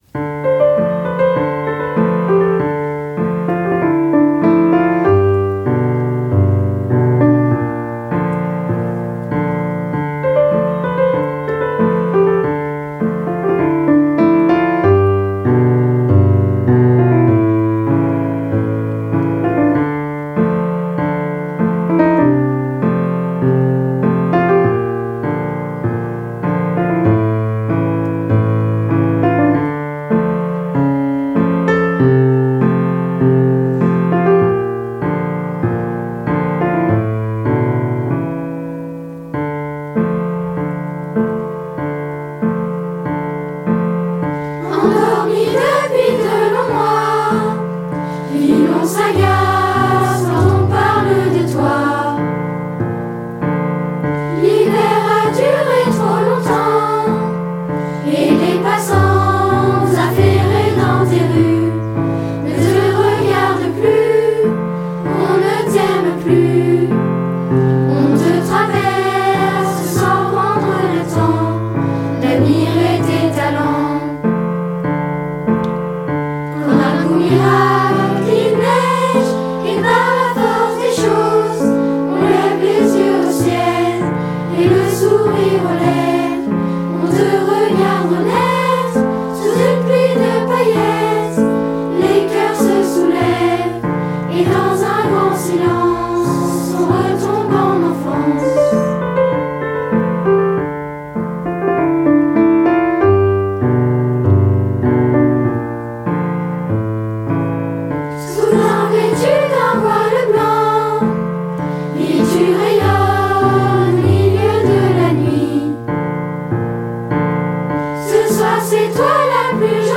En effet, les élèves de la chorale pendant cette semaine culturelle ont enregistré 7 chansons apprises cette année, afin de réaliser un CD.